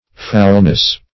Foulness \Foul"ness\, n. [AS. f[=u]lnes.]